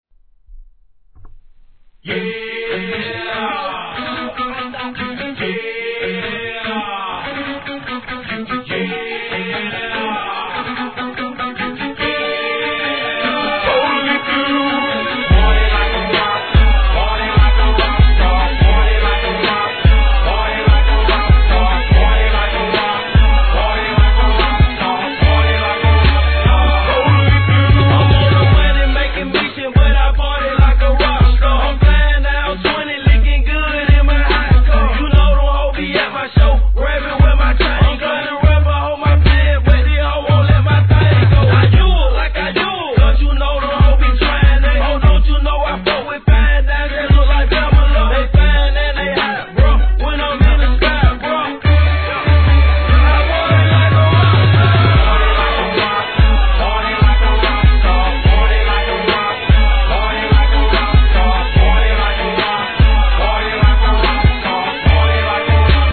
HIP HOP/R&B
ギターの乗った分かりやす〜いSOUTH BEATとフック、今後も勢い止まらないでしょう!!!